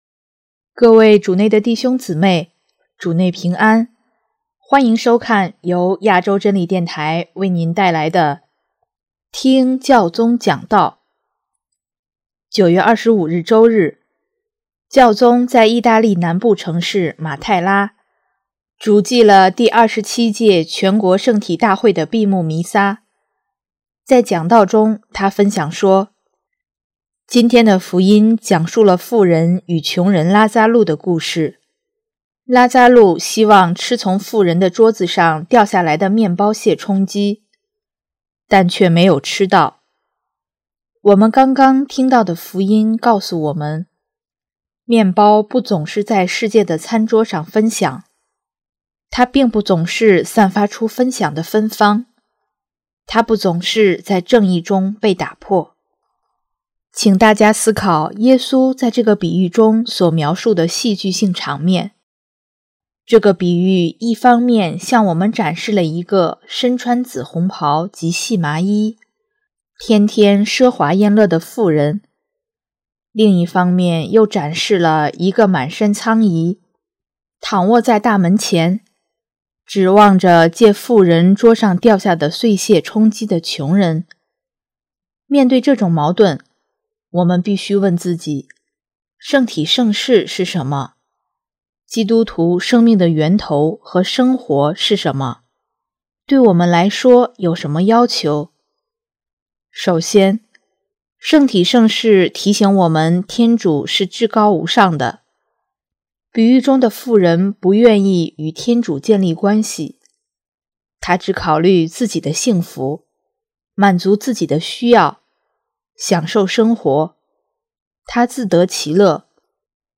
【听教宗讲道】|回归面包的味道
9月25日周日，教宗在意大利南部城市马泰拉，主祭了第27届全国圣体大会的闭幕弥撒，在讲道中他分享说：